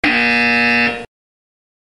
BUZZER